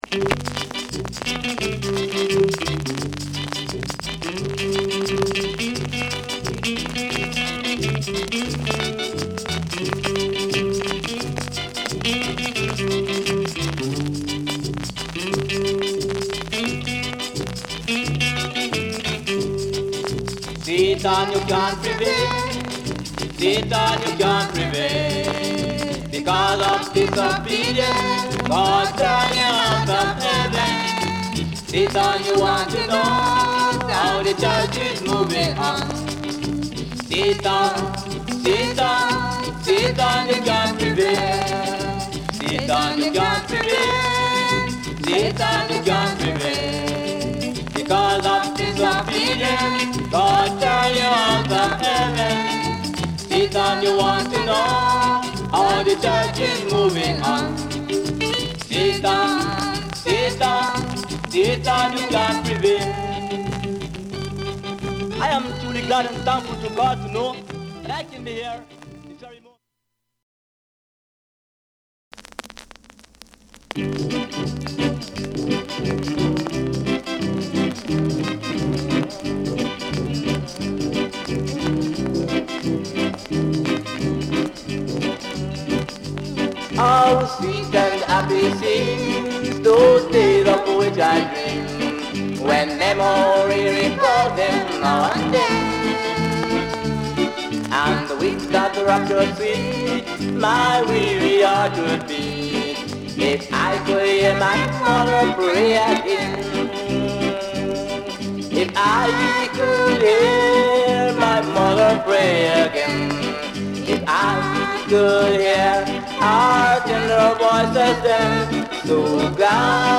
Genre: Reggae Gospel
ゴスペルとレゲエが融合したスタイル、ハーモニーを主軸とした落ち着いたアンサンブルが特徴。